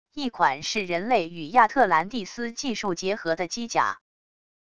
一款是人类与亚特兰蒂斯技术结合的机甲wav音频